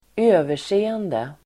Uttal: [²'ö:ver_se:ende]